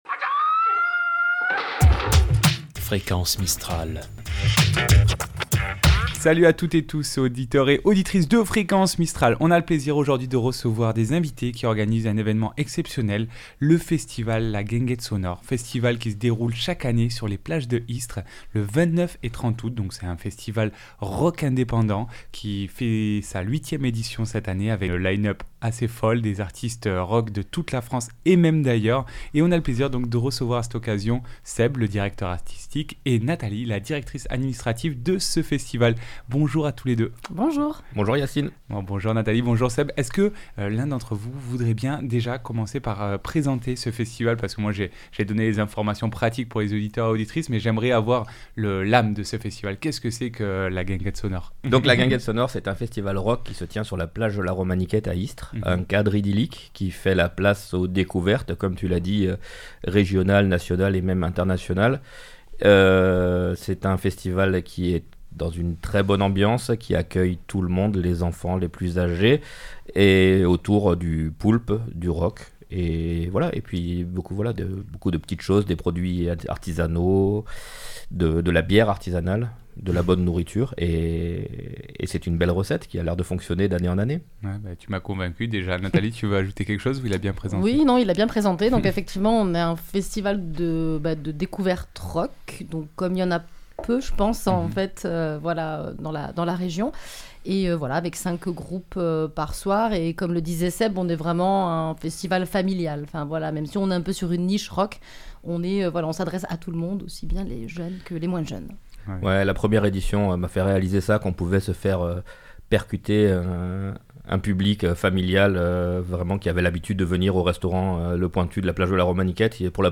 ITW Guinguette Sonore 2025.mp3 (20.72 Mo)